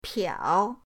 piao3.mp3